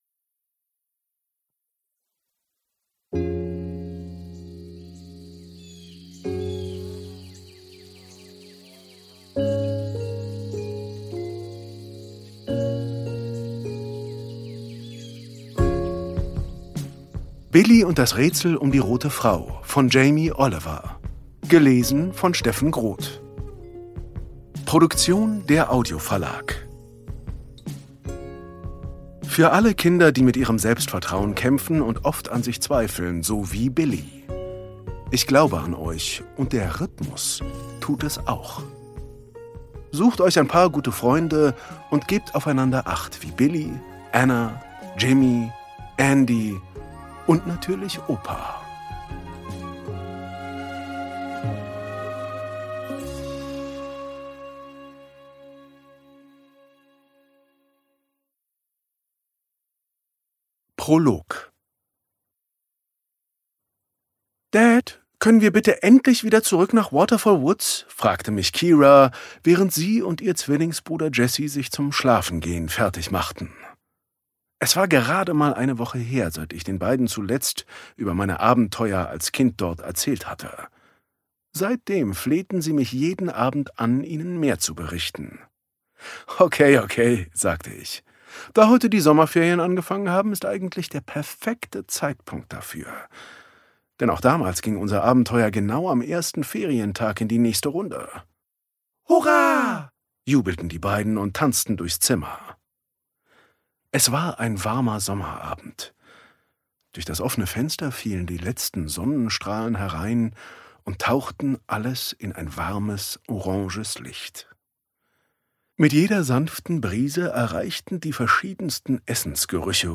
Steffen Groth (Sprecher)
Ungekürzte Lesung mit Musik mit Steffen Groth